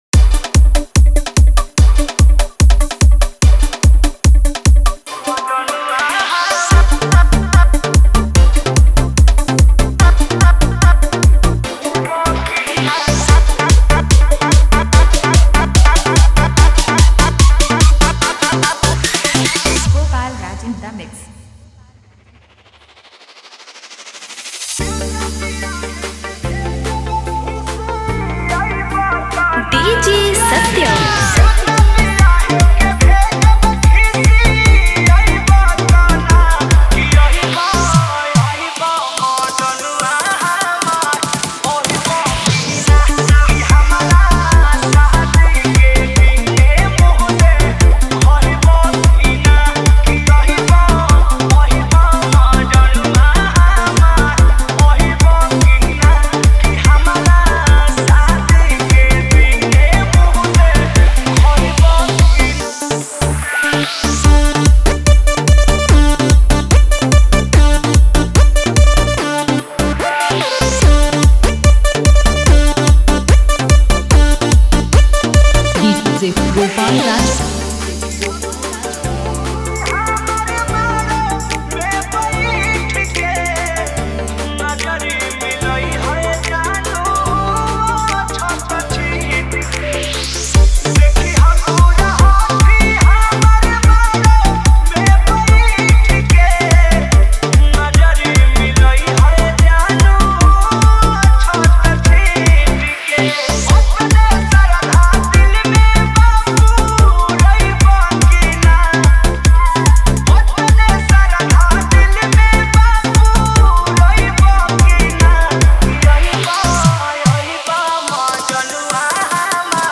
Category : Bhojpuri DJ Remix Songs